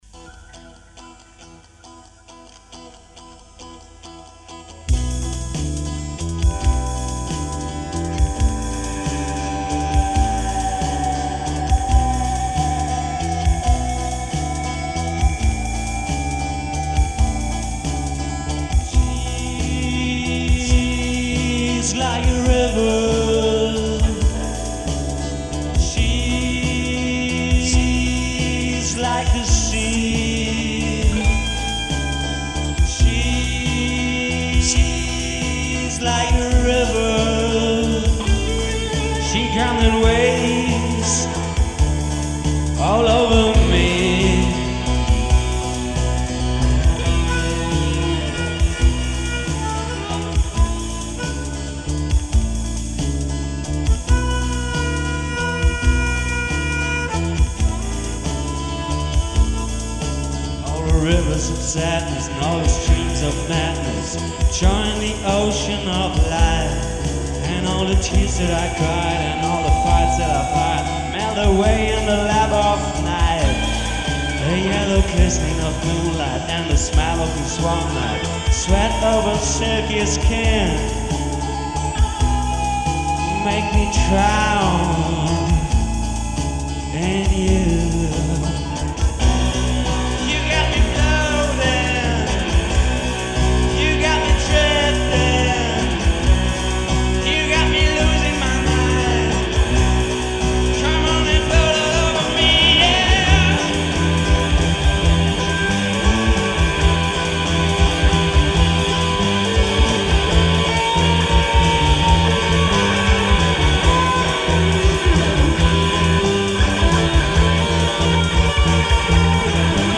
4-track-tape-recorded
noize-guitar
second voice
recording session